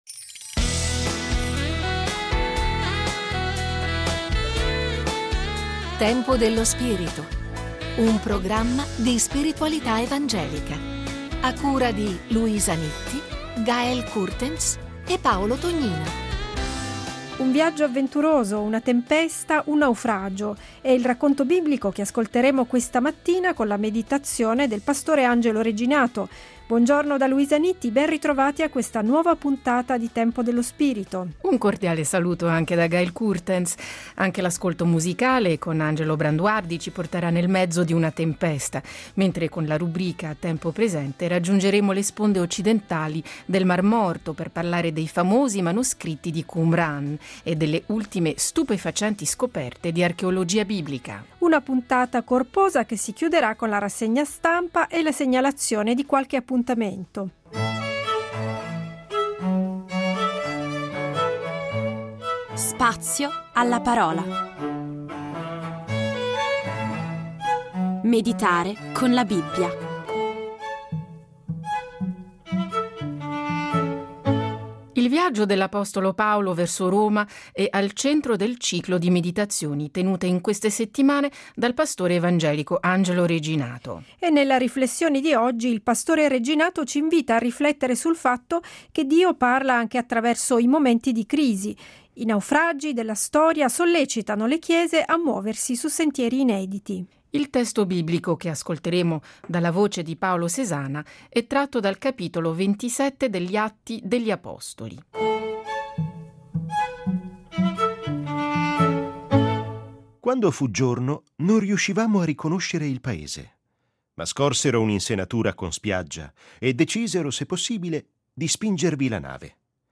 Meditazione biblica